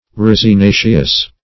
resinaceous.mp3